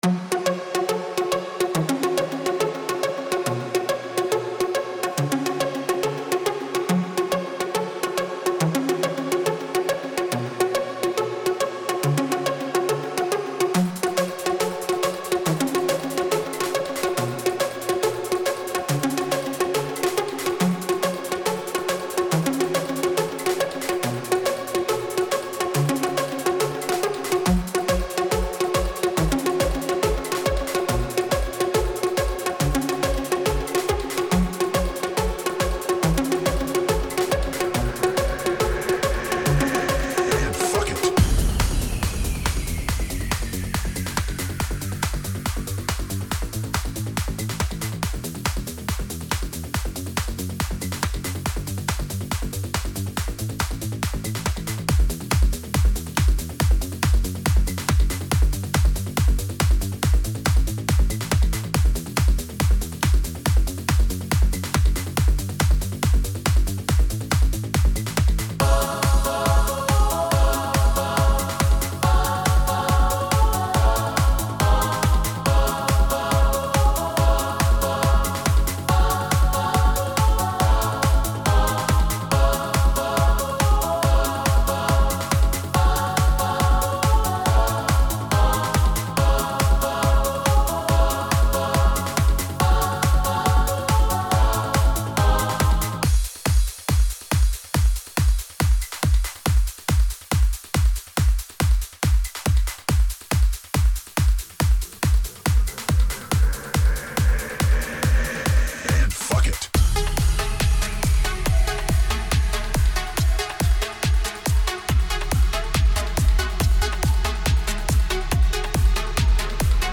Tempo 140BPM (Allegro)
Genre Trance/EDM/Dance
Type Vocal Music
Mood energetic